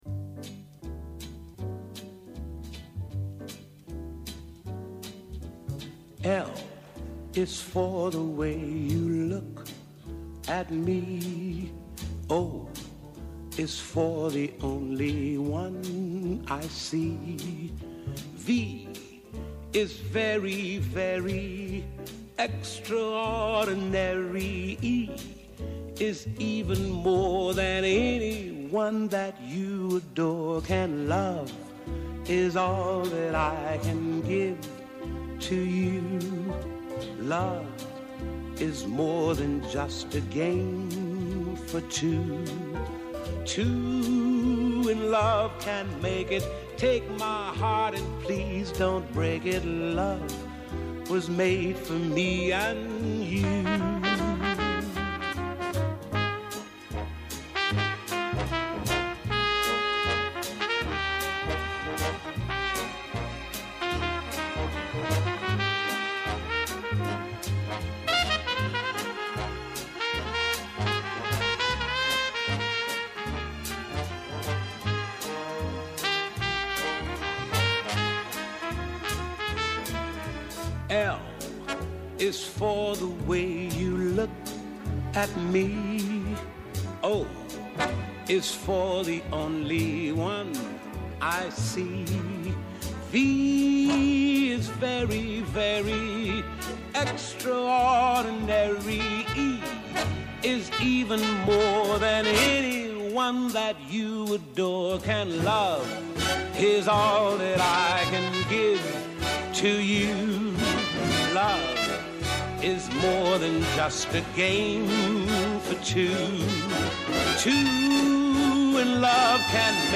Καλεσμένη σήμερα στο στούντιο της εκπομπής η Μίνα Γκάγκα, καθηγήτρια Πνευμονολογίας και πρώην αναπληρώτρια υπουργός Υγείας.